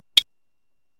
フィズ